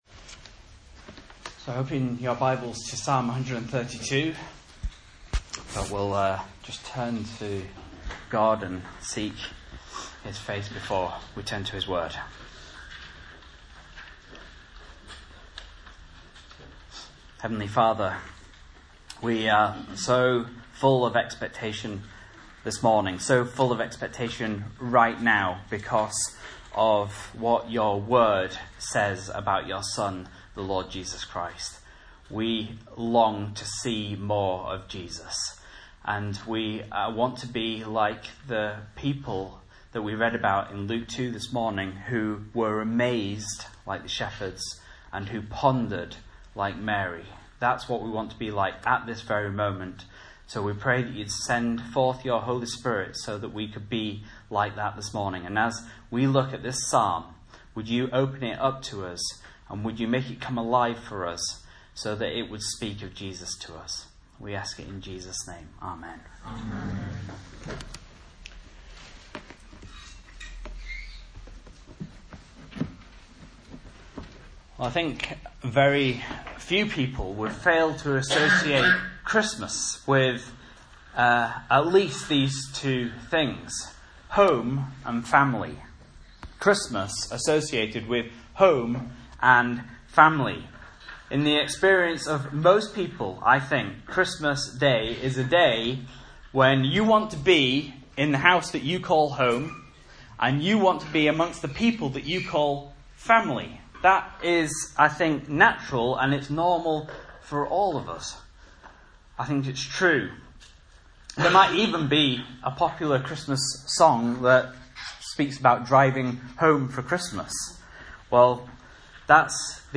Message Scripture: Psalm 132 | Listen